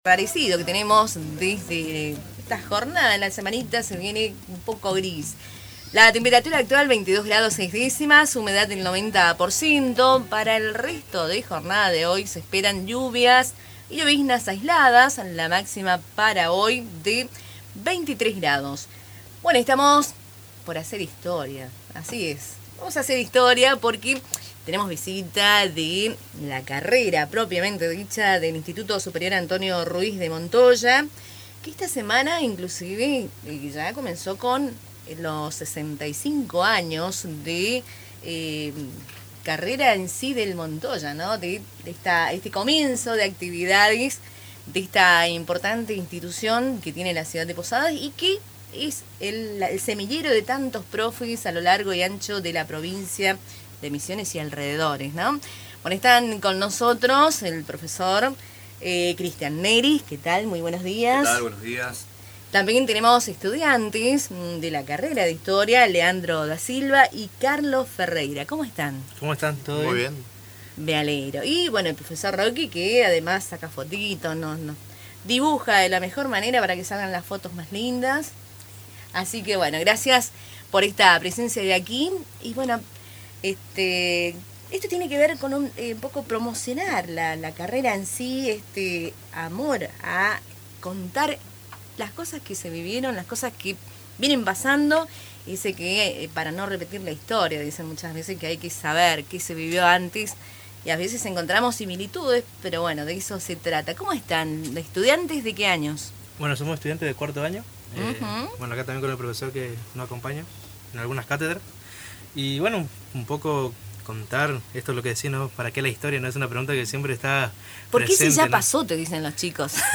Esta mañana, en el programa Cultura en diálogo de Radio Tupa Mbae, se compartió una enriquecedora conversación sobre la enseñanza de la historia y la carrera del Profesorado en Educación Secundaria en Historia del Instituto Superior Antonio Ruiz de Montoya (ISARM).